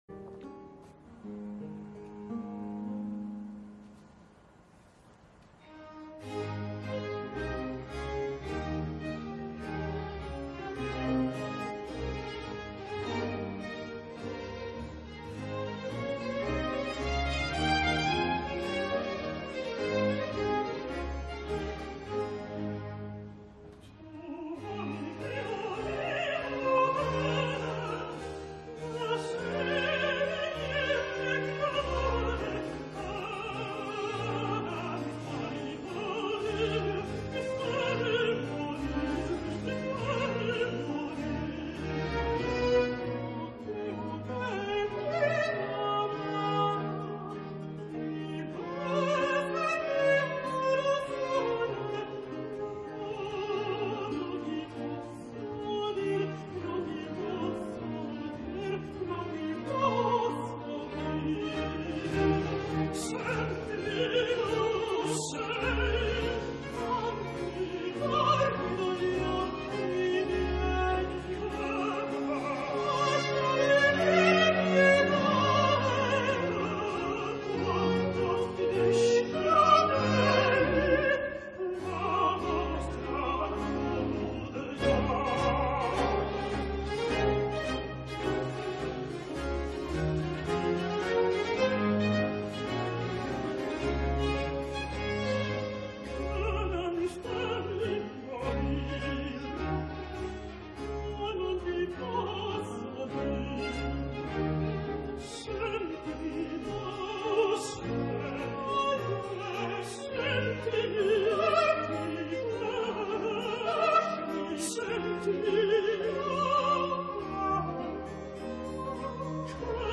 III, 7 Duetto Arbace Mandane Tu vuoi ch'io viva o cara.mp3 — Laurea Triennale in Scienze e tecnologie della comunicazione